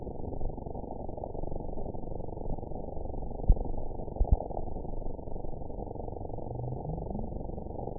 event 922713 date 03/17/25 time 20:37:55 GMT (1 month, 2 weeks ago) score 9.35 location TSS-AB03 detected by nrw target species NRW annotations +NRW Spectrogram: Frequency (kHz) vs. Time (s) audio not available .wav